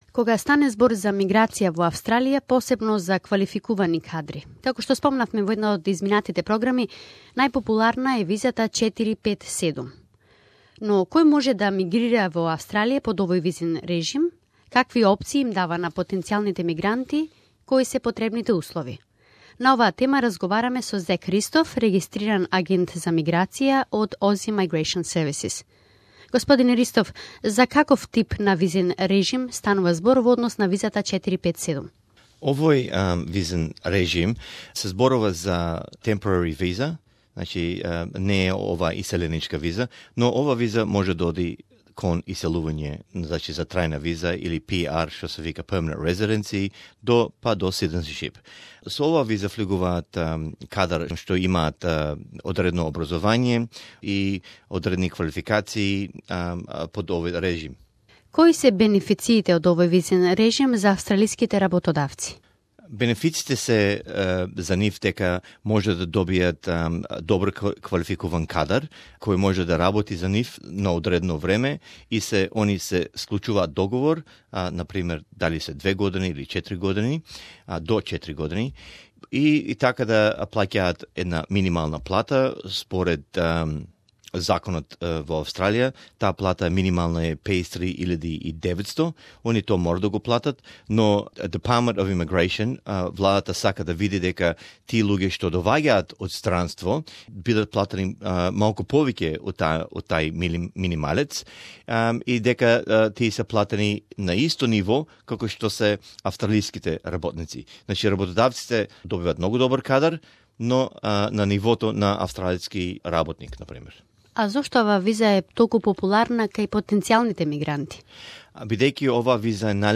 Австралиската виза 457 е популарна и практична и за австралиските работодавци и за квалификуваните потенцијални мигранти, носејќи ги талентираните и вредните полесно и побрзо до австралиско државјанство. Интервју